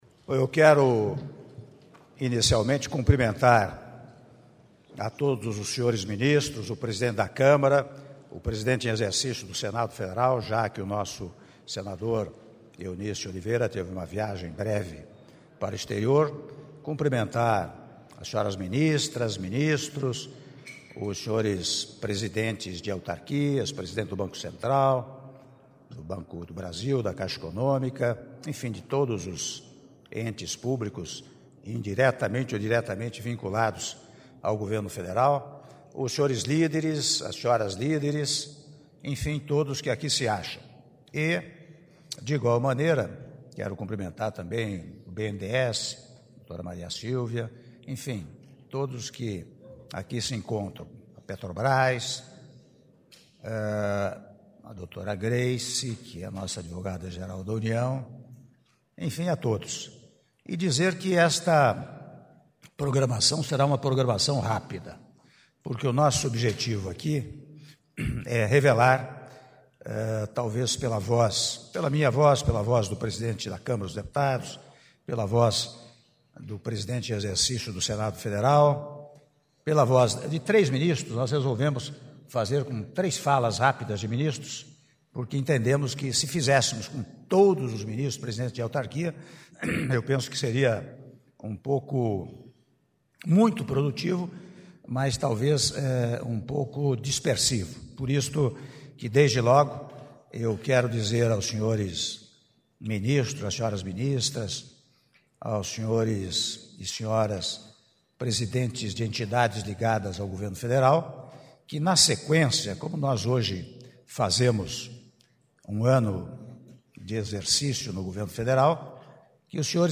Áudio do discurso do presidente da República, Michel Temer, na abertura da reunião "Um Ano de Conquistas"- Brasília/DF- (03min17s)